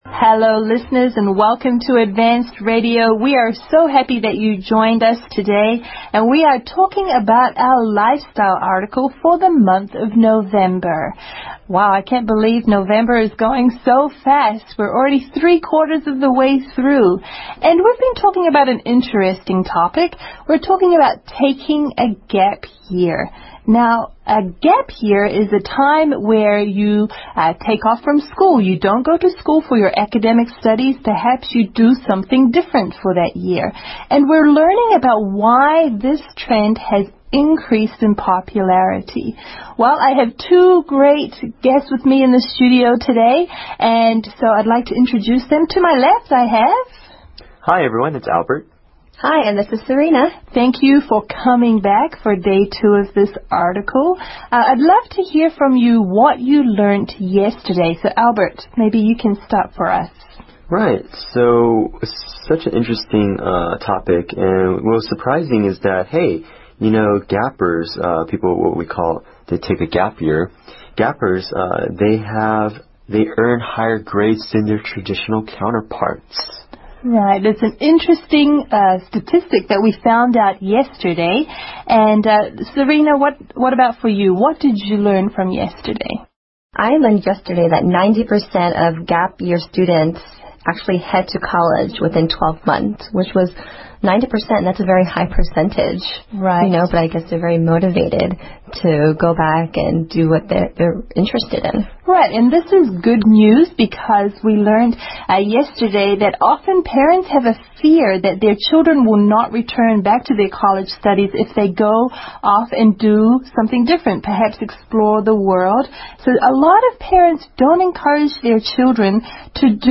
《空中英语教室》主要以收录世界最新时尚资讯、热点话题、人物、故事、文化、社会现象等为主，以谈话聊天类型为主的英语教学节目。